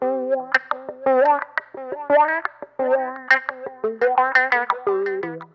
VIBRAWAH.wav